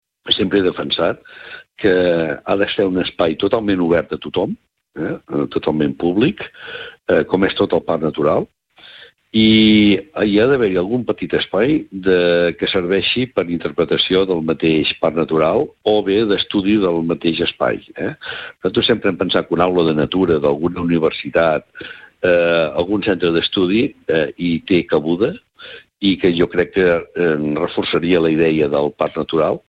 Avui al Supermatí n’hem pogut parlar amb Jordi Colomí, alcalde del municipi.